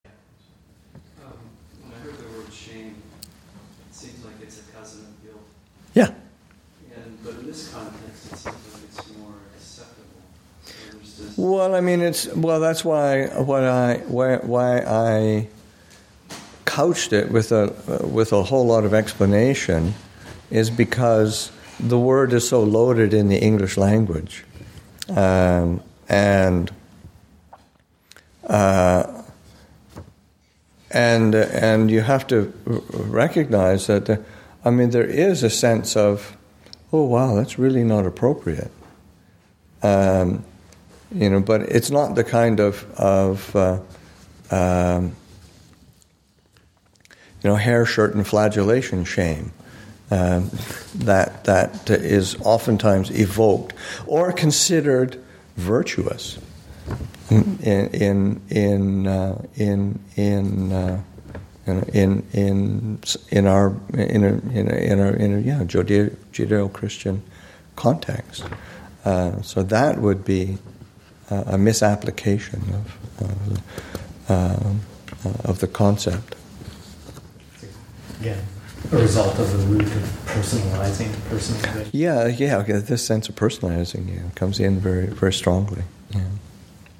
[Session] Readings: AN 7.6: Treasures.